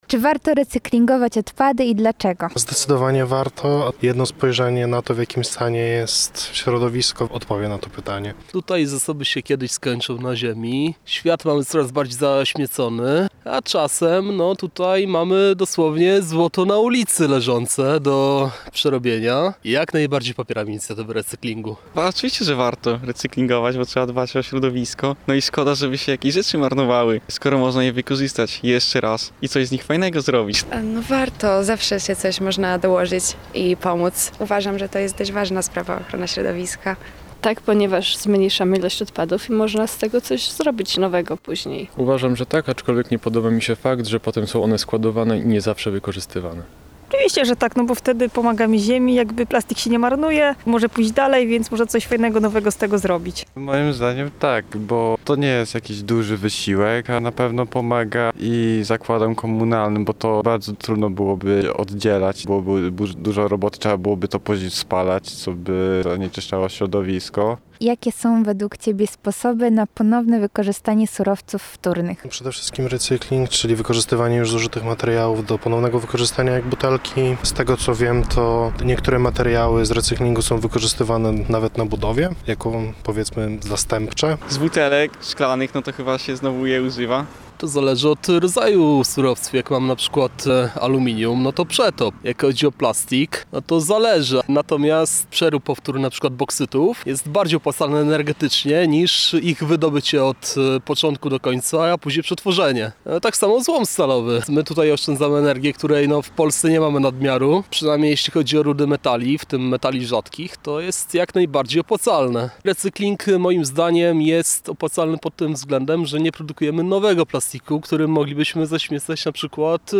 Z okazji Światowego Dnia Recyklingu nasza reporterka postanowiła sprawdzić, co o recyklingu sądzą studenci i jakie mają sposoby na ponowne wykorzystywanie surowców wtórnych.